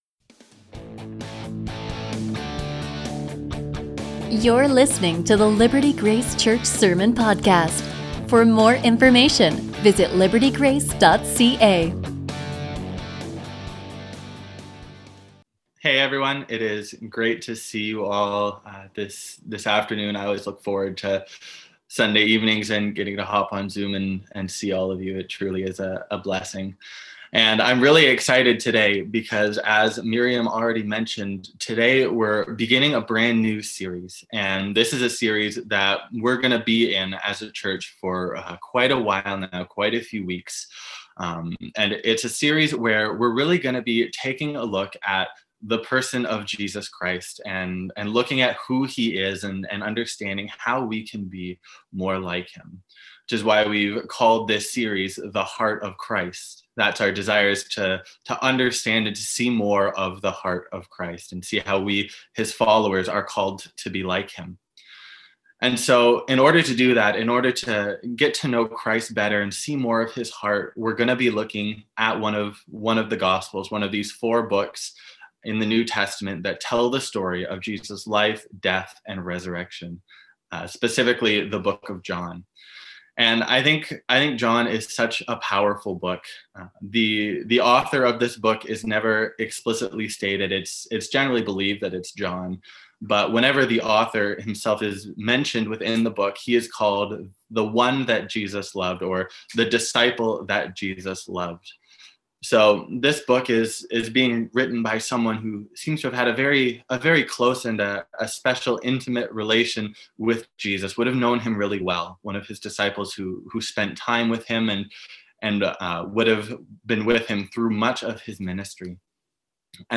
A sermon from John 13:1-20